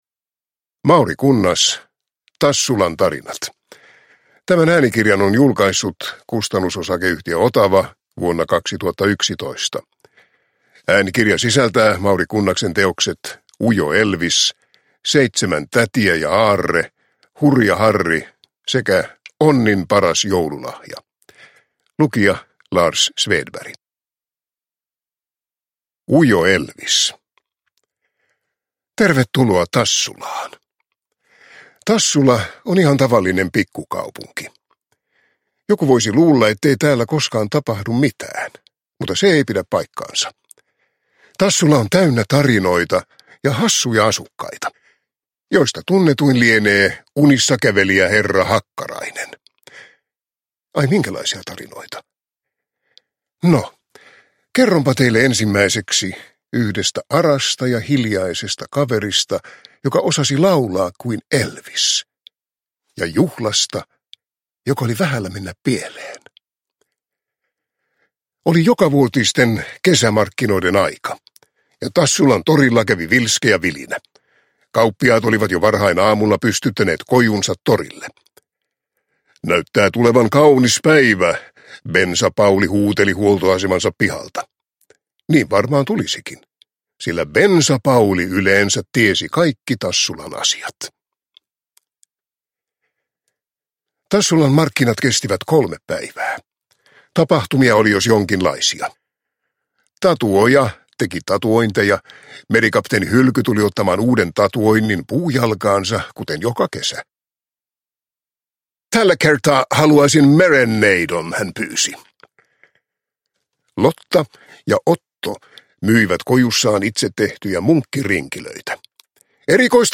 Tassulan tarinat – Ljudbok – Laddas ner